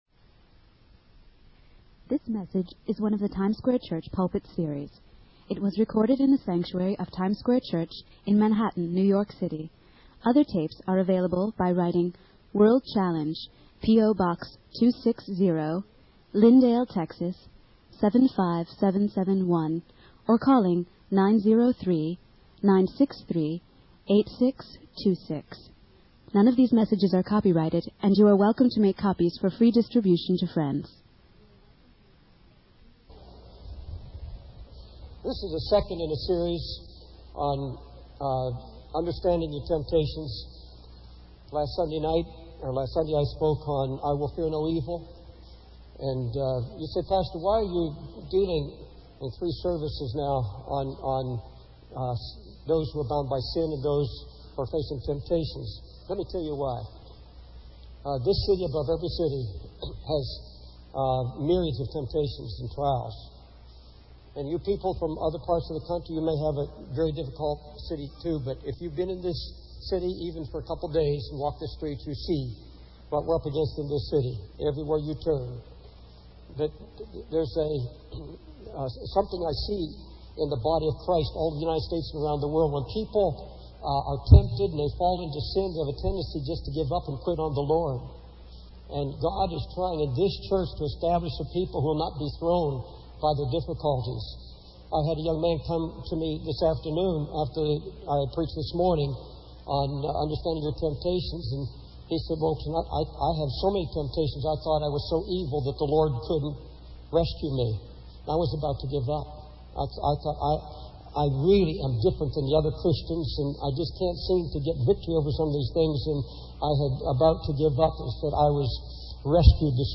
In this sermon, the preacher shares his personal struggle with temptation and feeling different from other Christians.